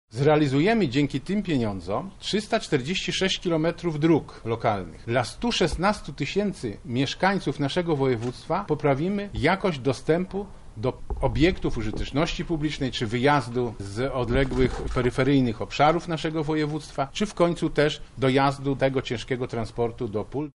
– mówi marszałek województwa Sławomir Sosnowski.